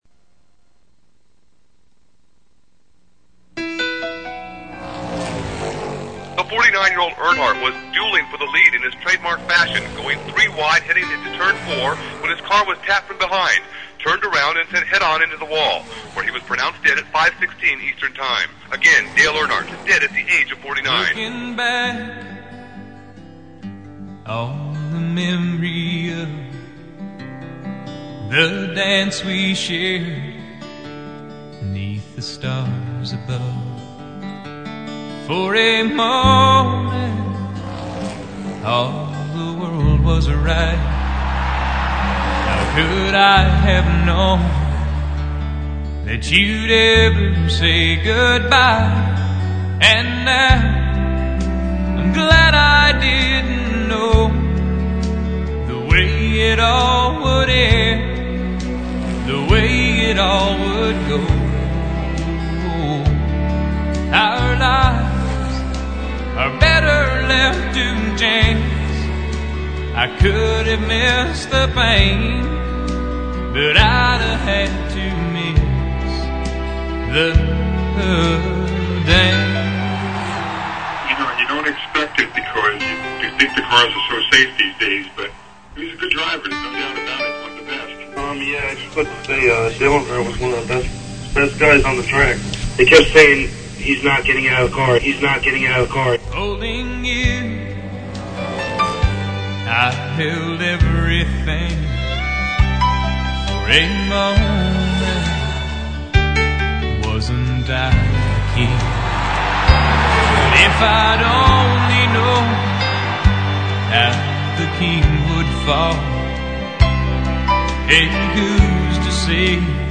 Tribute song heard on WGNA - Albany, NY